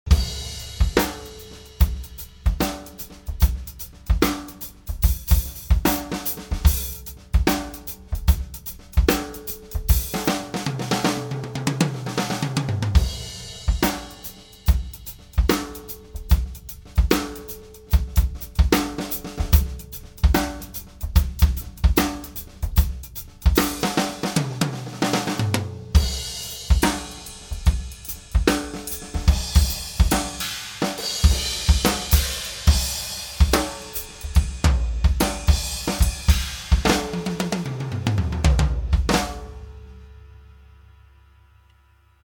Snare: Shure SM57 (ca. 10cm vom Spannreifen auf die Fellmitte schauend)
Bass- und Snare-Mikro waren auf übliche Weise mit dem EQ bearbeitet und in die Mitte gepannt.
Der Raum ist ein zu ca. 2/3 mit Noppenschaum ausgekleideter Kellerraum von vielleicht 3m x 5m. Die Deckenhöhe ist leider nicht groß und wird durch ein ca. 20cm hohes Drumpodest noch weiter verringert - nicht ideal für Overhead-Aufnahmen. Überhaupt ist der Raum zwar akustisch zum Proben ganz gut, aber für Aufnahmen eigentlich zu trocken und in den Höhen recht gedämpft. Ich war überrascht, das die Technik trotzdem so gut funktioniert hat...
MKH40- mit Kick- und Snare-Stützmikros - ganzes Set - mastered
Glyn-Johns_MKH40_OH-Kick-Snare_ganzes-Set_mastered.MP3